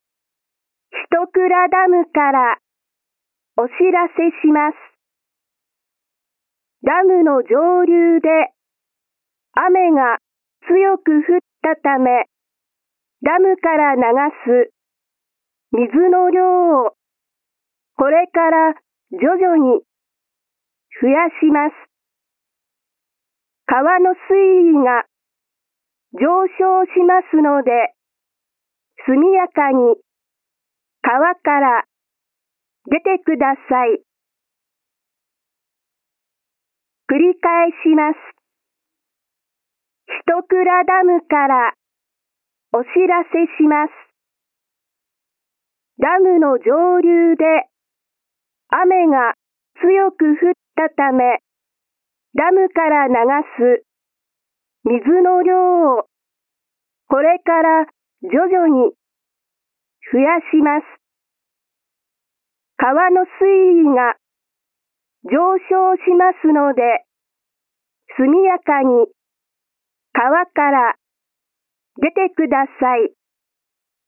■ダムから量の多い水を流すときは、警報のサイレンやスピーカー
音声放送